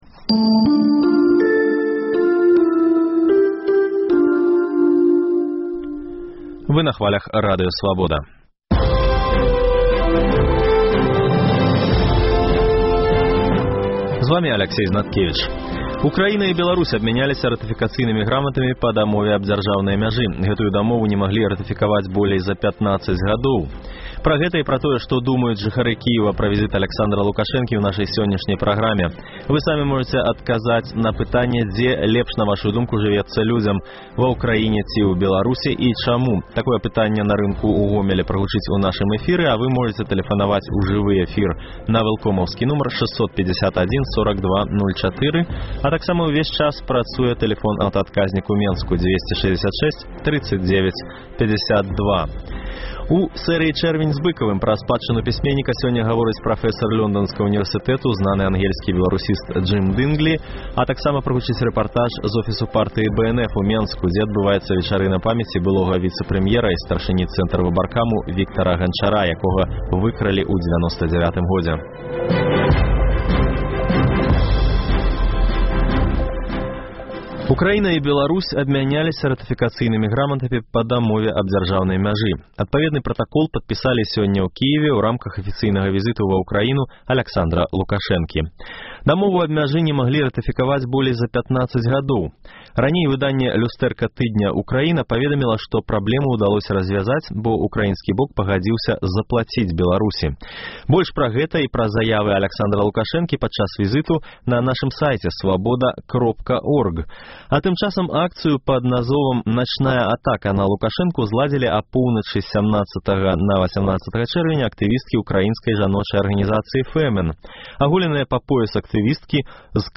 Апытаньне на рынку ў Гомелі, дзе шмат і ўкраінцаў, і беларусаў.